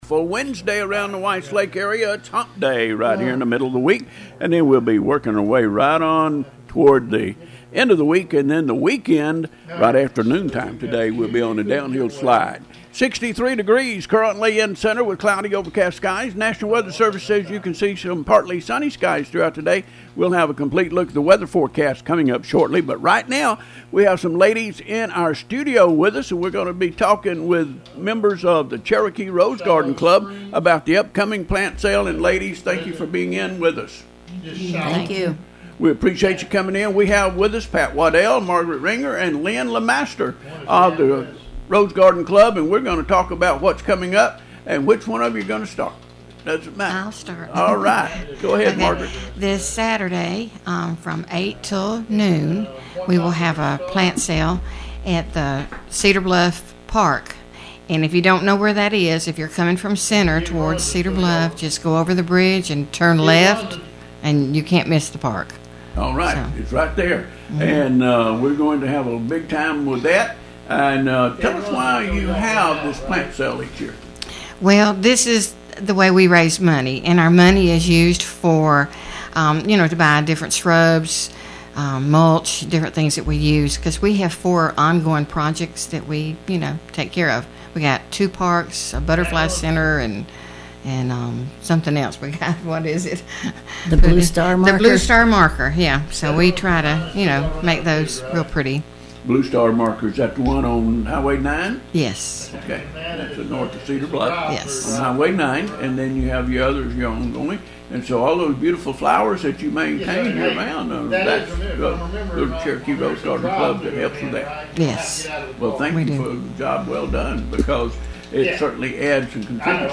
Cherokee County- The Cherokee Rose Garden Club was in Studio with WEIS Radio on Wednesday morning talking about their upcoming plant sale.